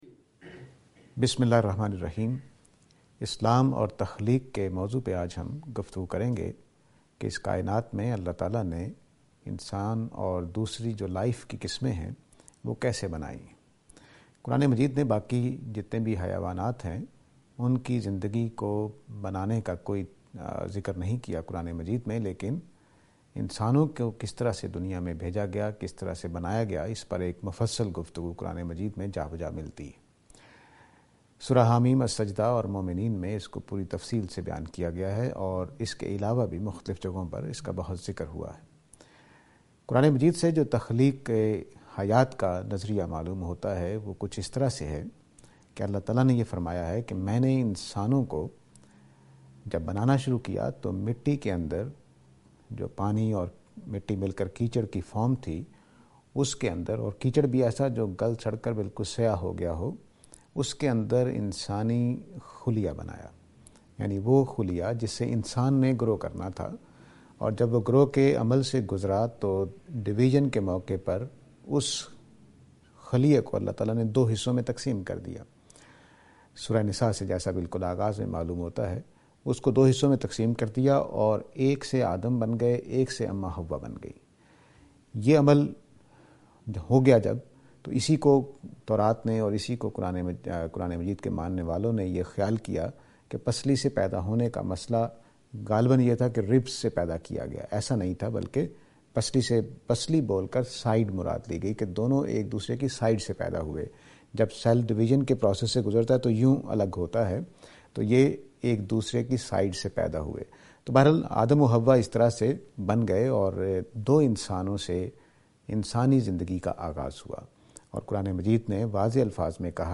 This lecture is and attempt to answer the question "Creation of Life: Quranic perspective".